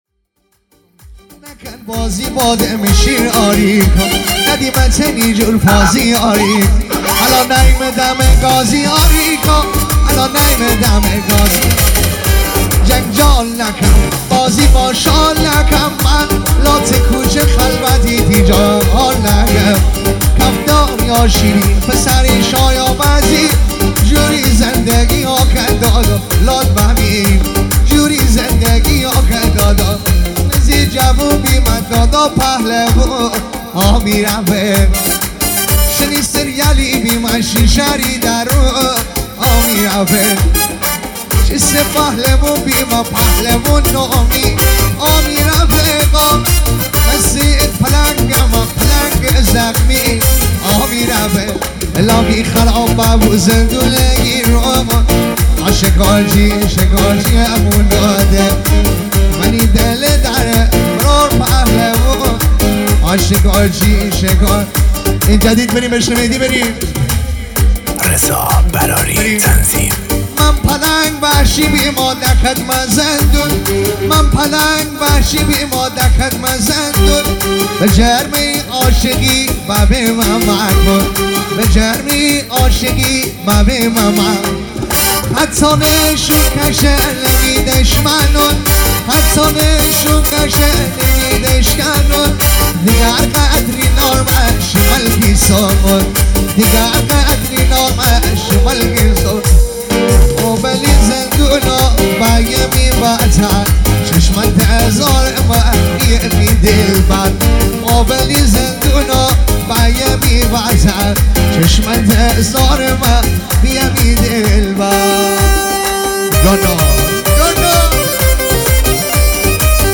جشنی مجلسی